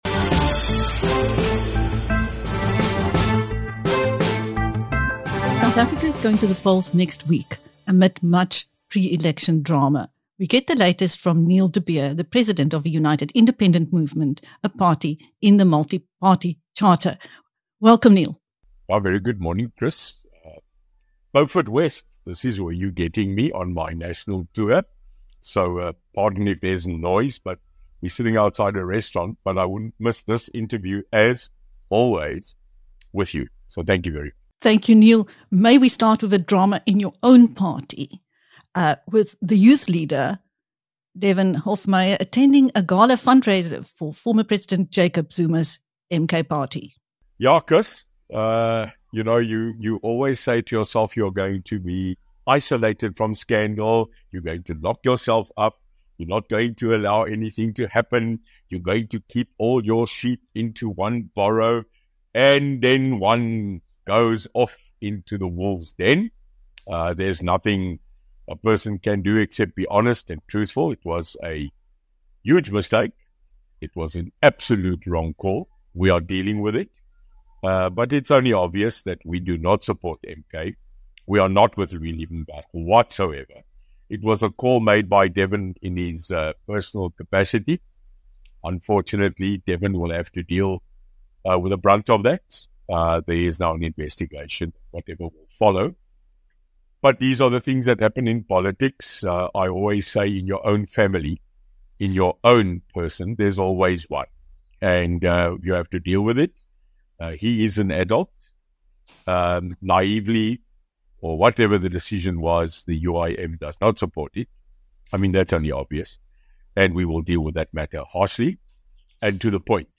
In this pre-election interview with BizNews